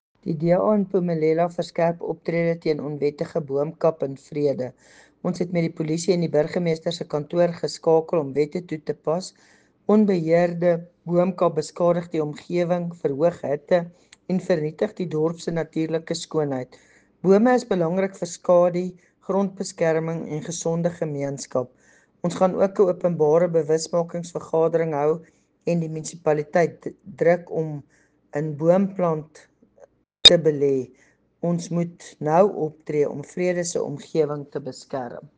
Afrikaans soundbites by Cllr Doreen Wessels and